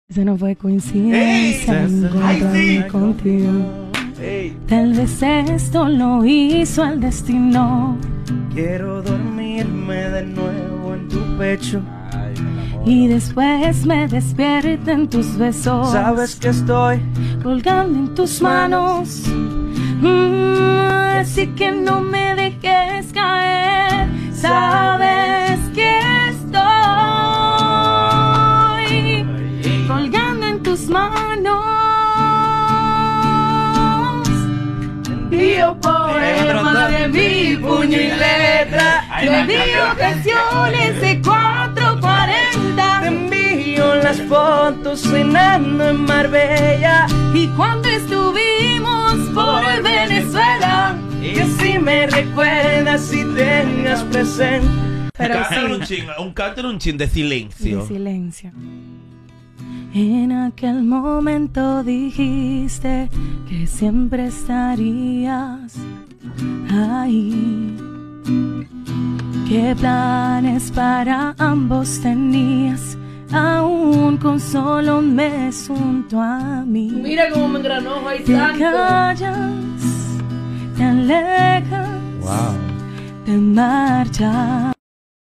demuestran su talento musical en vivo